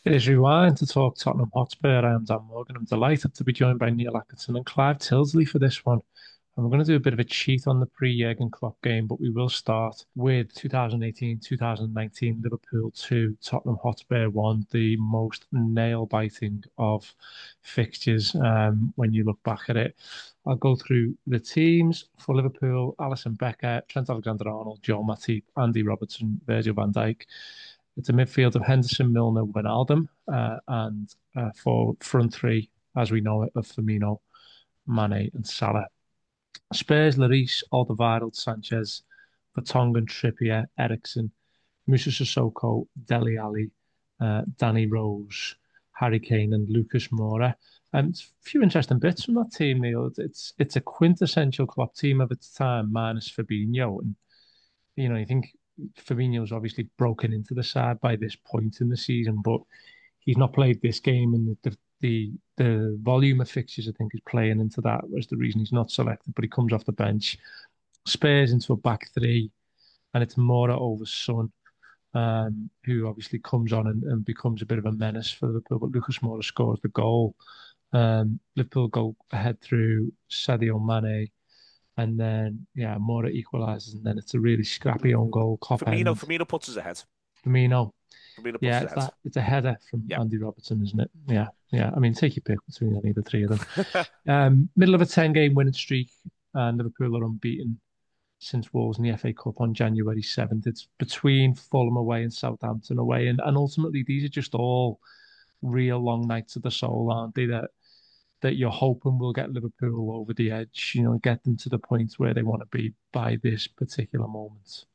Below is a clip from the show – subscribe to The Anfield Wrap for more Liverpool chat…